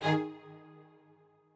strings6_45.ogg